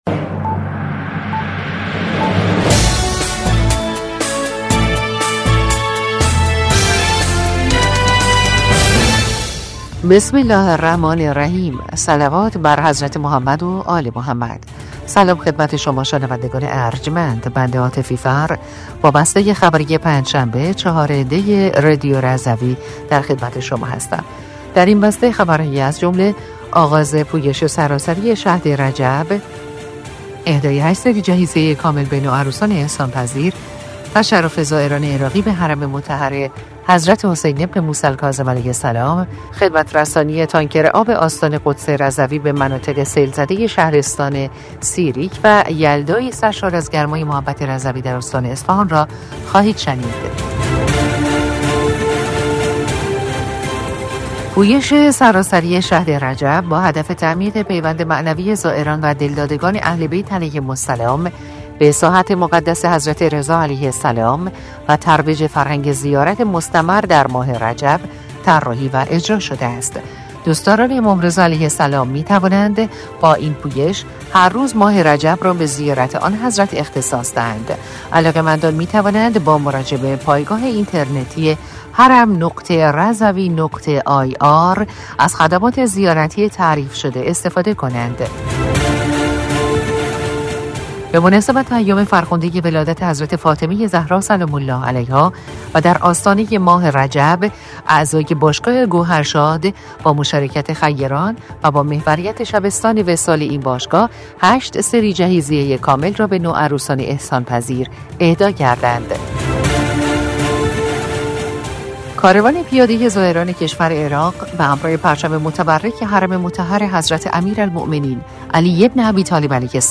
بسته خبری ۴ دی ۱۴۰۴ رادیو رضوی؛